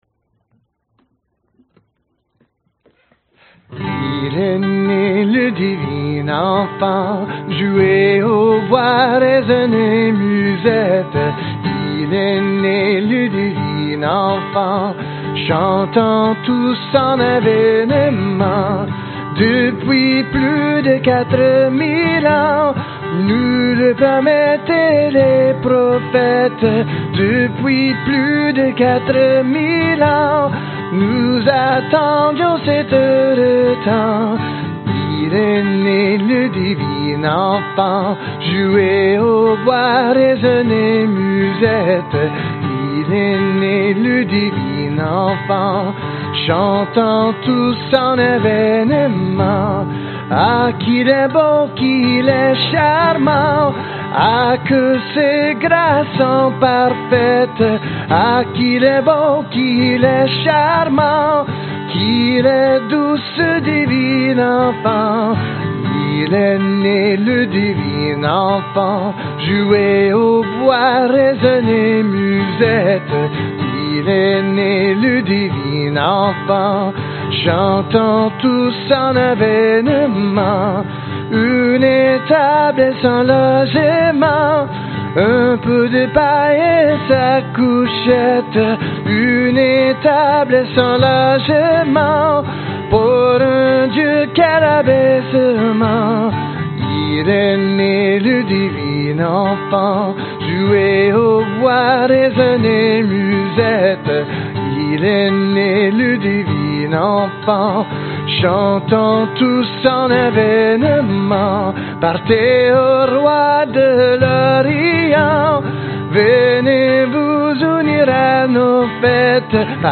描述：这是一首传统的法国圣诞歌曲，G调 G调，法国传统圣诞赞美诗。
Tag: 节日 圣诞 传统 法国 法语